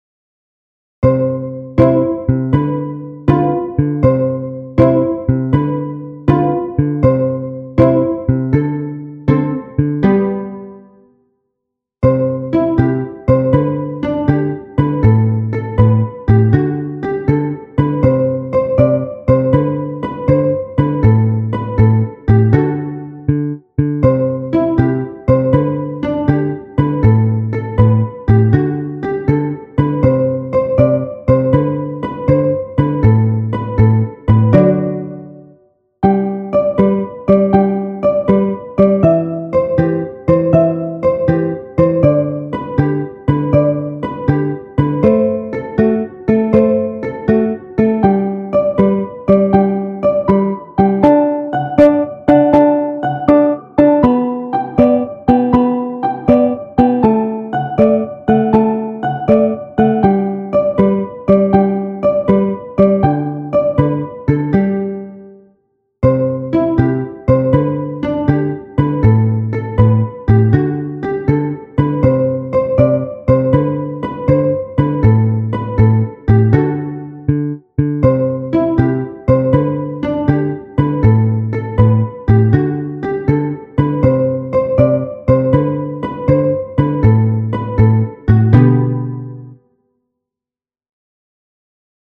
ギターのエフェクターを試してみたくなりました。